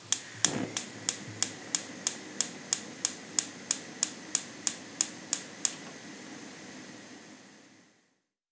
• starting the cooking machine.wav
starting_the_cooking_machine_x1n_w5a.wav